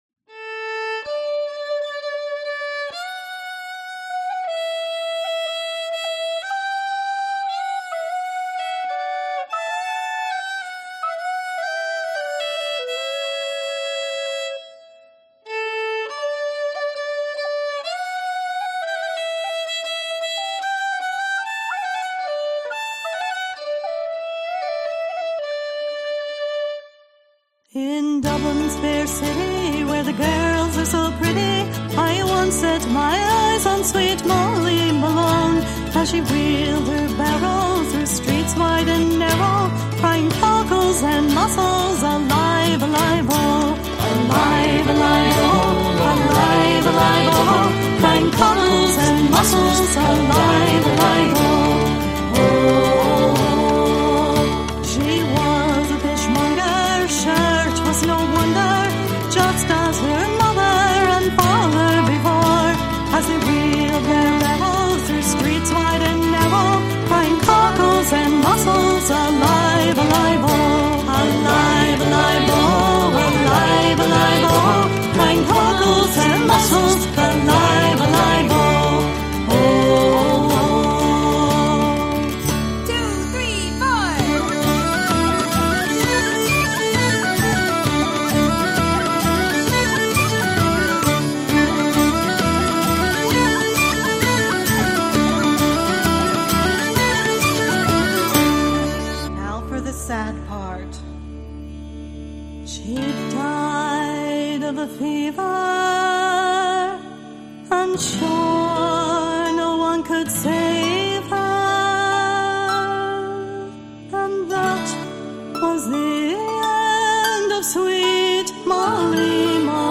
Canción típica de San Patricio